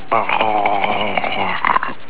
Homer Drooling 17k
homerdrool.wav